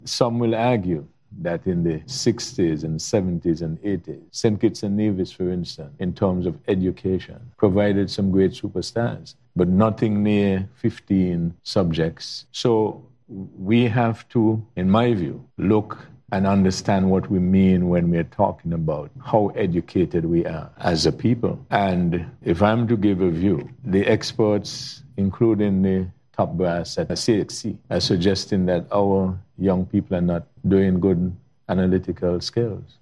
During a recent panel discussion based on the general development of St. Kitts and Nevis, the topic of the nation’s education status came to the fore.